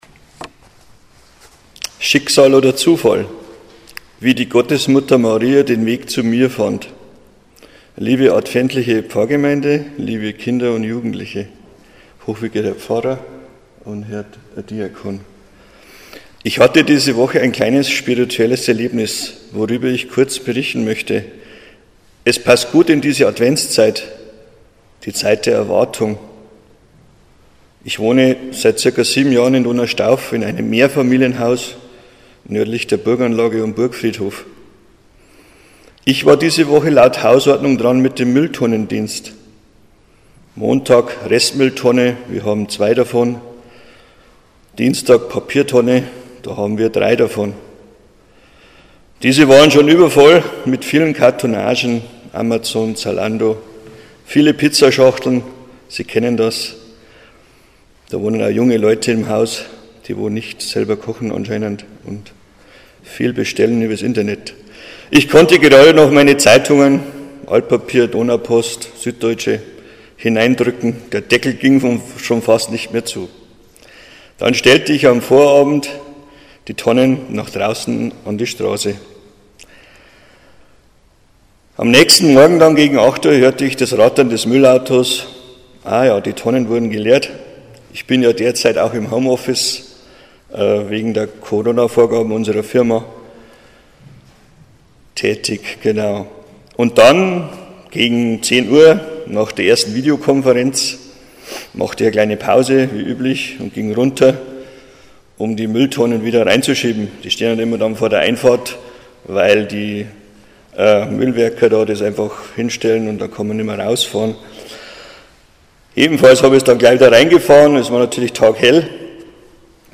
Ein Zeugnis